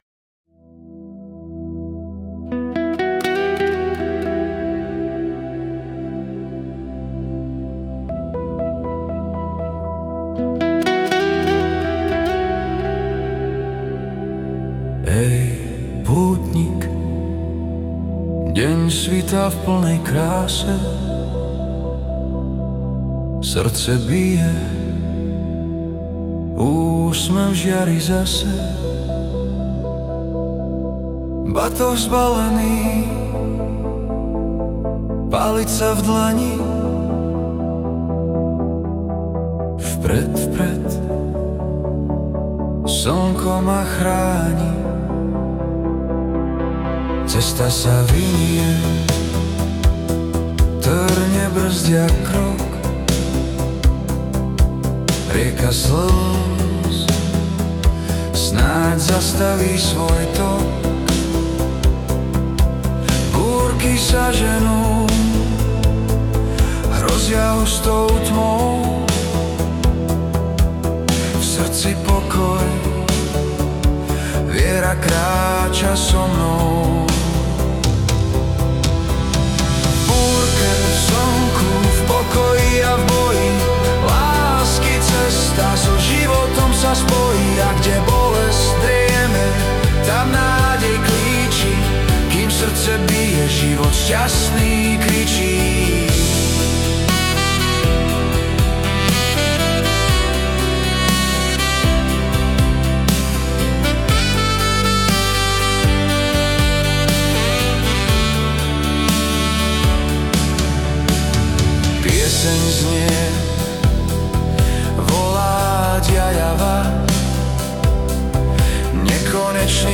Hudba a spev AI
Balady, romance » Romantické